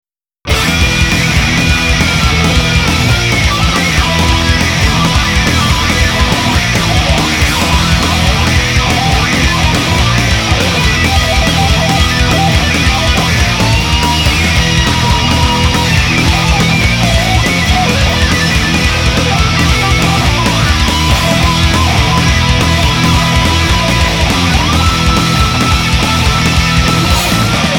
Назад в Metal